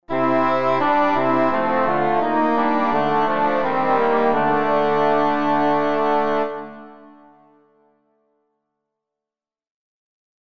Binchois_Rendre_me_vieng_D.mp3